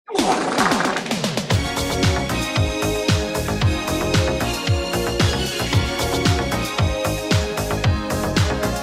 Rick Roll Wet Fart - Free Sound Effect - MemeHub
Rick Roll Wet Fart